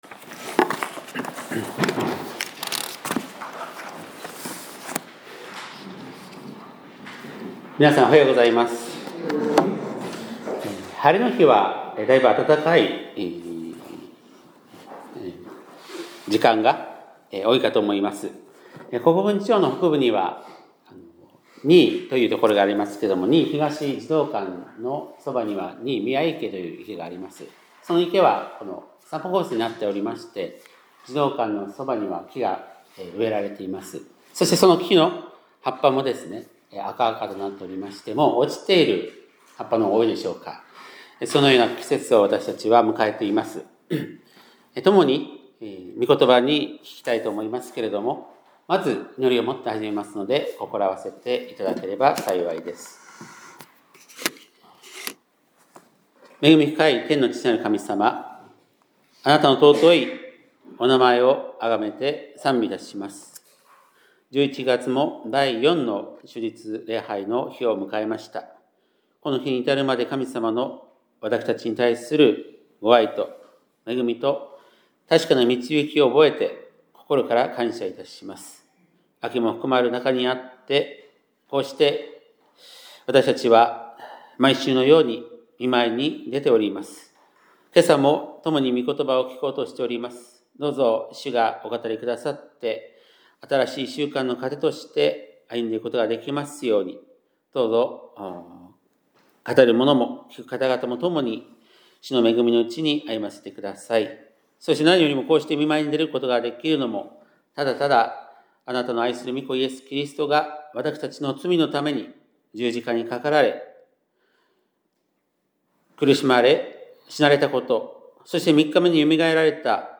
2025年11月23日（日）礼拝メッセージ